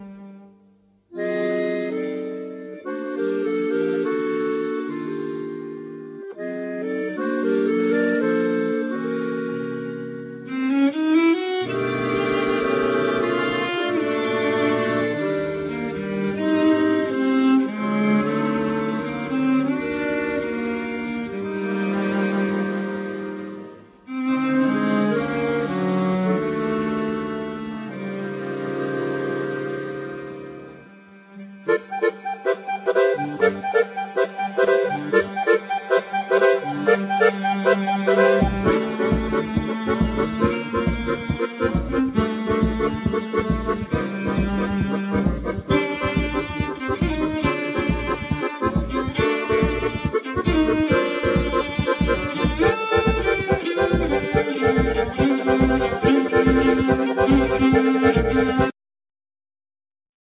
Cello Accordeon Vibraphone,Percussins